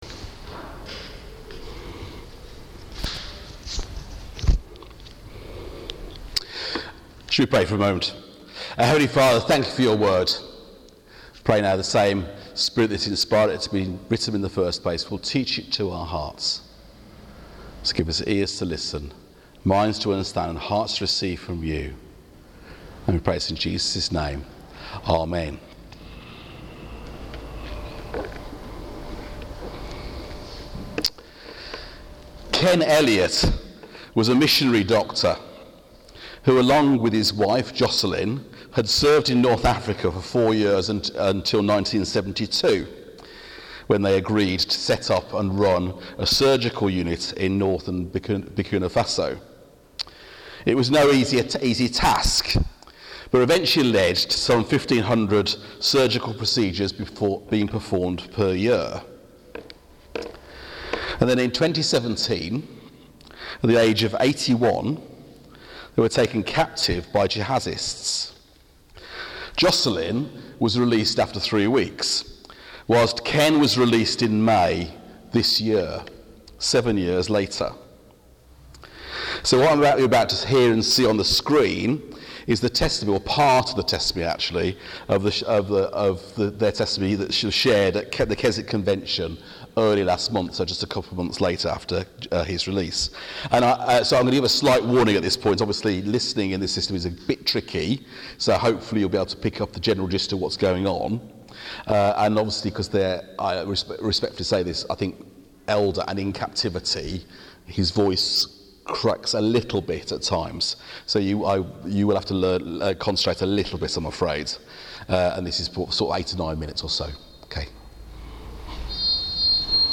2 Timothy 1:8-18 Service Type: Morning Service The link for the video shown in the service is here .